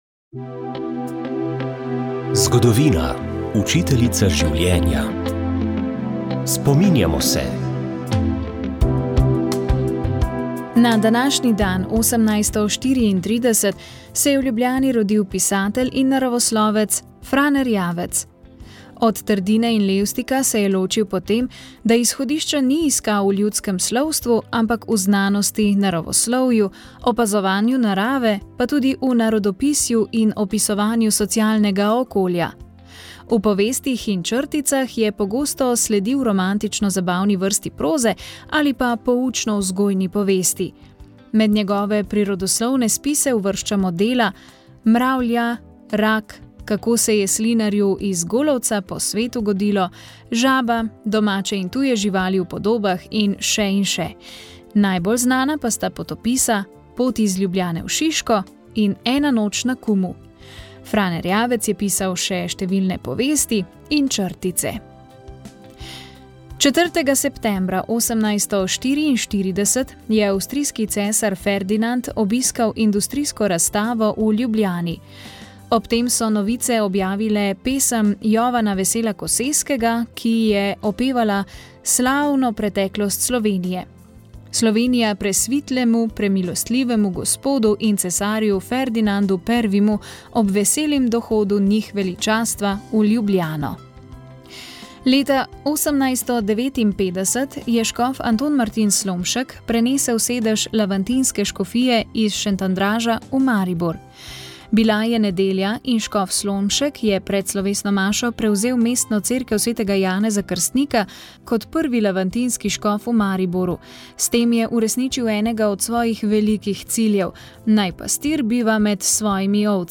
Tokrat je bil na programu 28. del radijskega romana Dragulj v pesku Tesse Afshar, ki je izšla pri založbi Družina. Slišite lahko kako so Hebrejci nadaljevali z osvajanjem Kanaanske dežele.